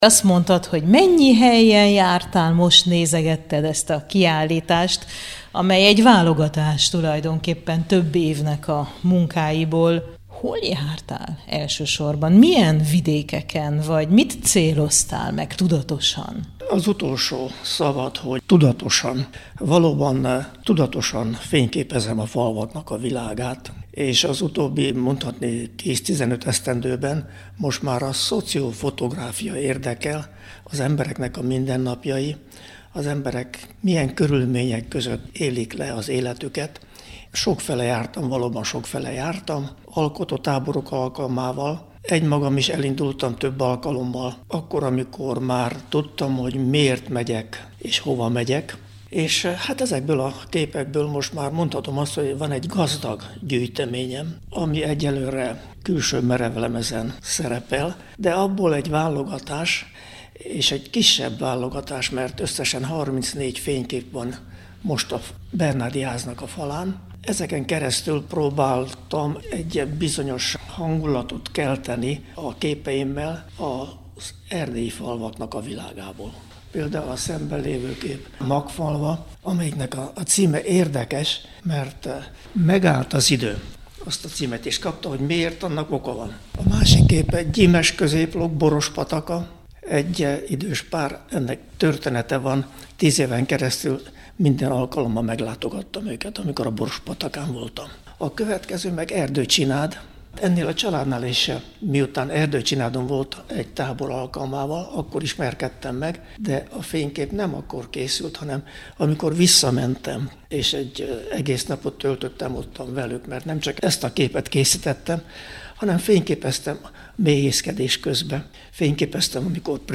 Tárlatvezetés